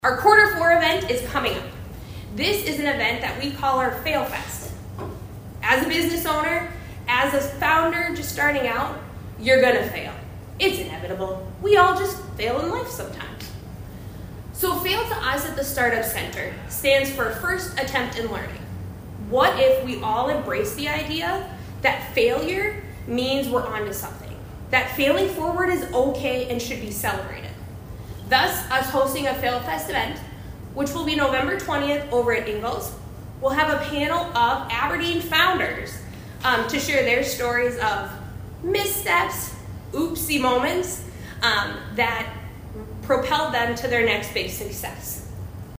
ABERDEEN, S.D.(HubCityRadio)- The Aberdeen Chamber of Commerce’s Chamber Connections Series continued Thursday at the K.O.Lee Public Library.